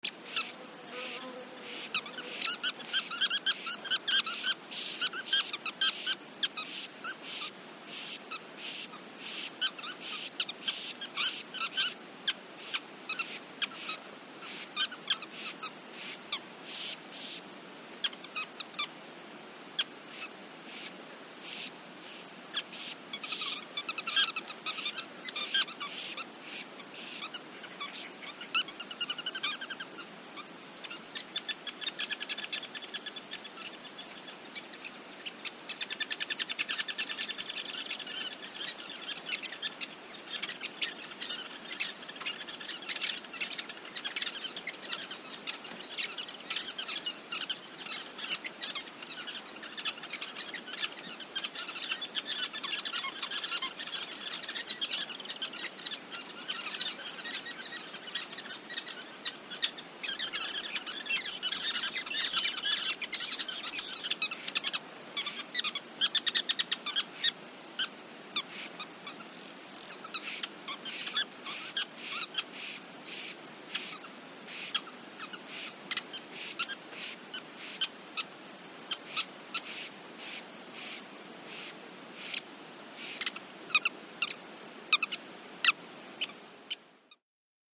Sociable Weavers, large colony, Kalahari Desert